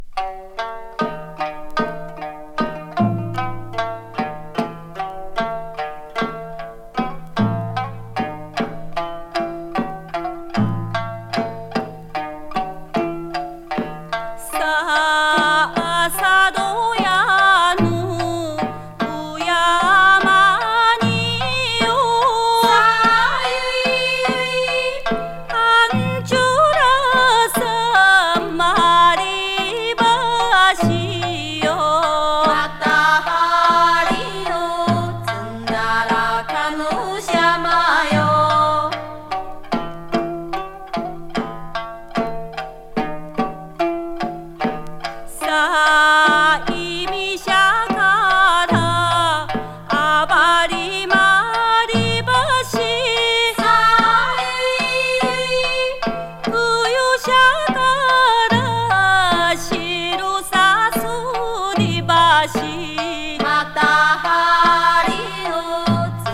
沖縄民謡コンピレーションアルバム